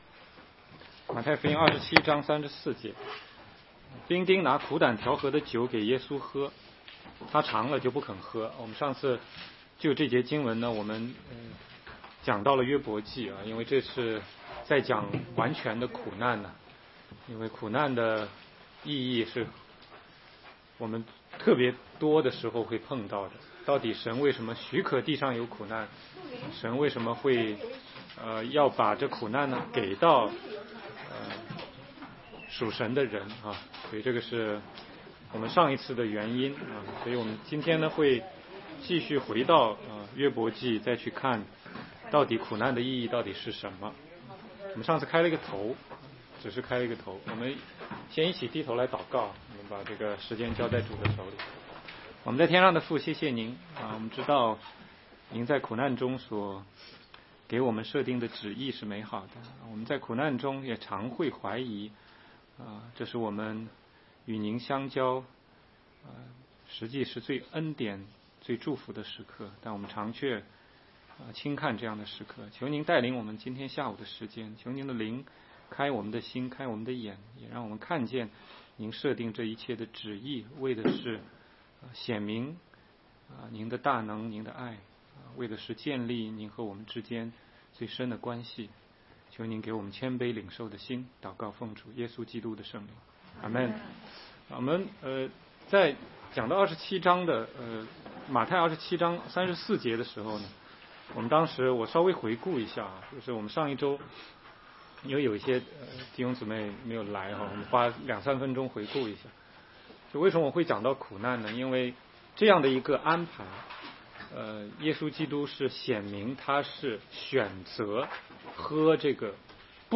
16街讲道录音 - 马太福音